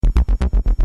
Oberheim - Matrix 1000 18